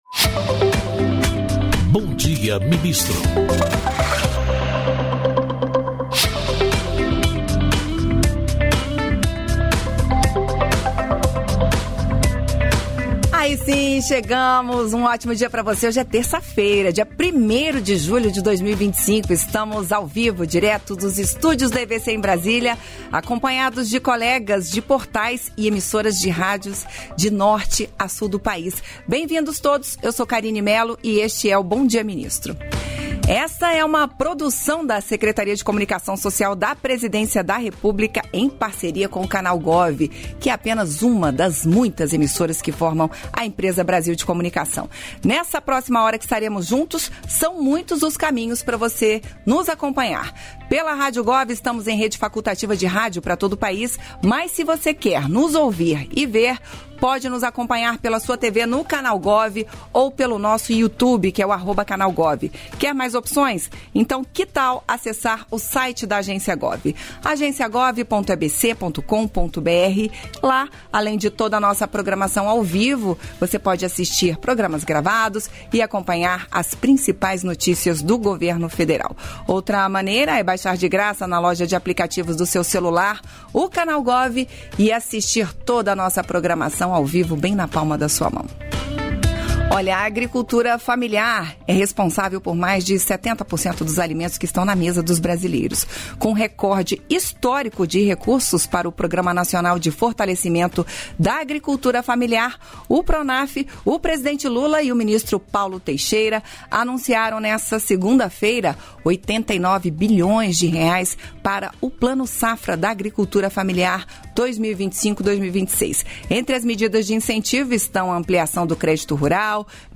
Trecho da participação do ministro da Secretaria-Geral da Presidência da República, Márcio Macêdo, no programa "Bom Dia, Ministro" desta quarta-feira (18), nos estúdios da EBC em Brasília (DF).